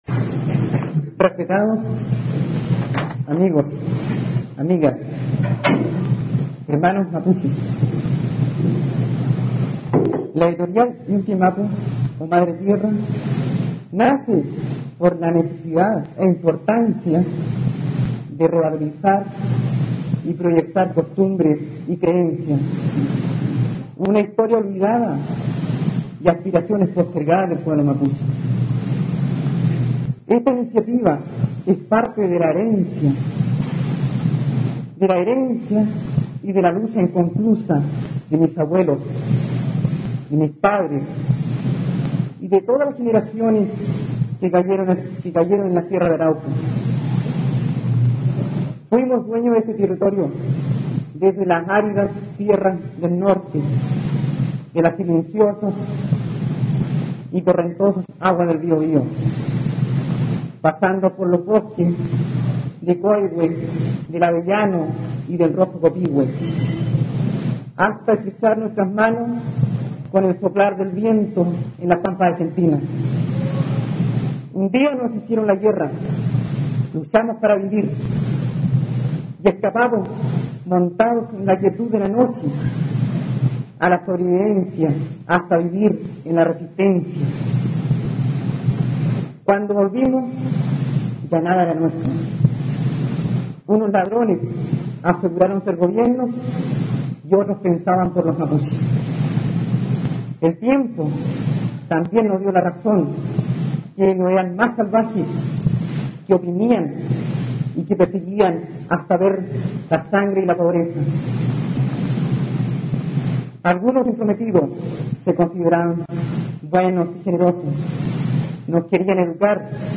Dicha presentación, realizada en la sede de la Sociedad de Escritores de Chile, constituye también una fuerte reivindicación de la cultura mapuche.